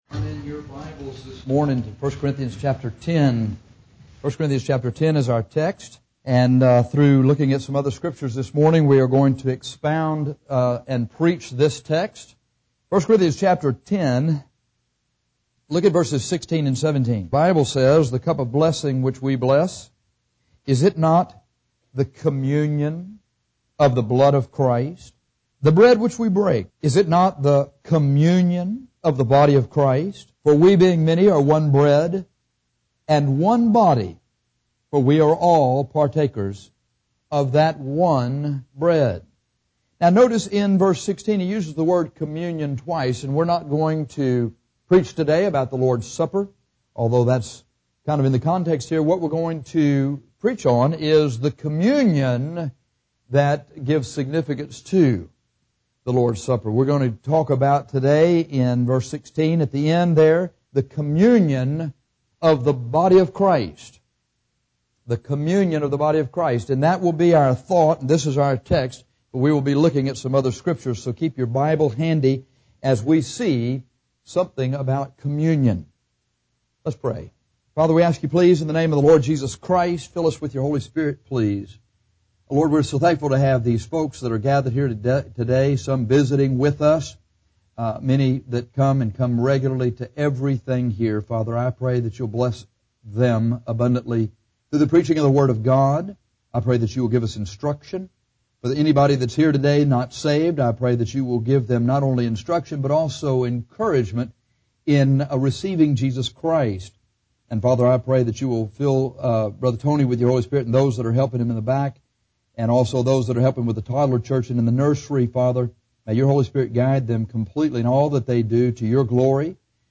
However, in this sermon we are not going to deal with the Lord’s Supper.